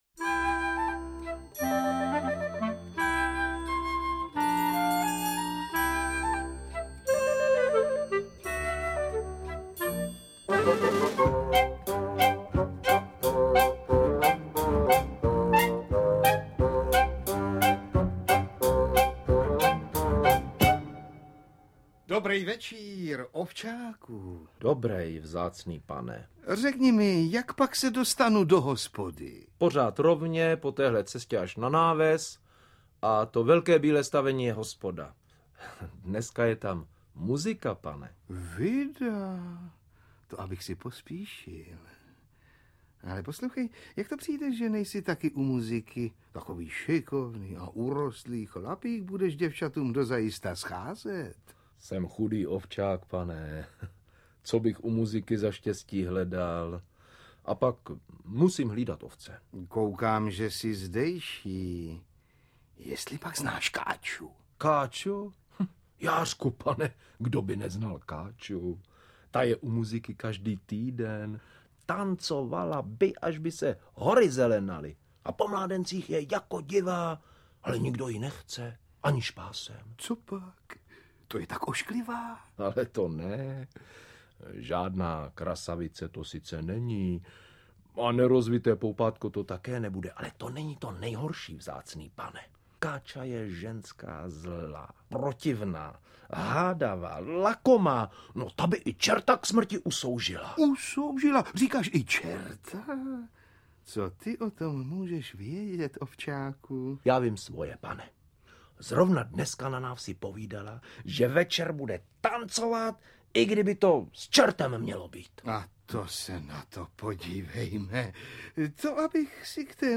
Interpreti:  Jana Andresíková, Vlastimil Bedrna, Zuzana Bydžovská, Miloš Hlavica, Ladislav Mrkvička, Alois Švehlík, Miroslav Vladyka
Na motivy národních pohádek napsali Jan Kramařík a Jaroslav Nečas. V dramatizovaných nahrávkách z roku 1990 účinkují Václav Postránecký, Zuzana Bydžovská, Ivan Vyskočil, Josef Langmiler, Jan Přeučil ... (Čert a Káča) a Ladislav Mrkvička, Miroslav Vladyka, Andresíková Jana, Gabriela Vránová, Růžena Merunková ... (Tři zlaté vlasy děda Vševěda).
AudioKniha ke stažení, 2 x mp3, délka 59 min., velikost 54,2 MB, česky